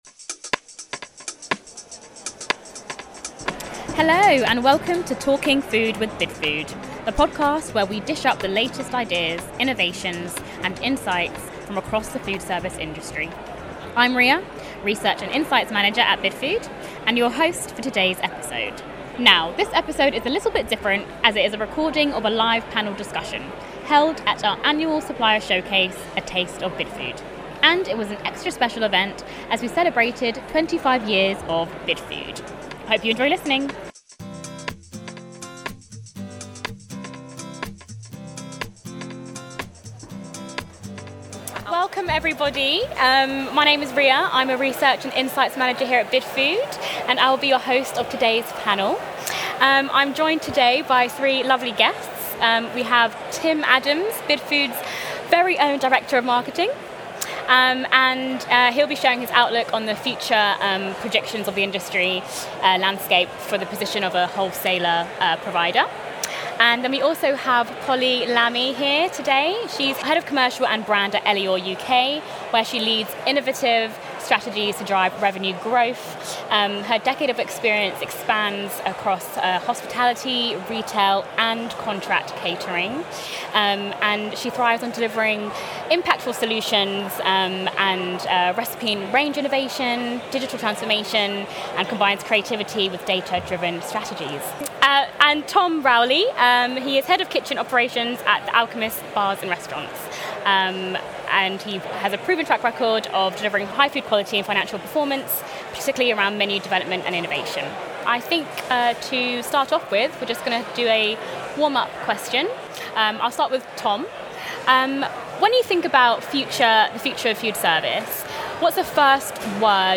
In this episode of Talking food with Bidfood, industry experts from Elior UK, The Alchemist and Bidfood UK discuss what’s next for foodservice and what it takes to stay ahead in a rapidly changing market, straight from the A Taste of Bidfood panel!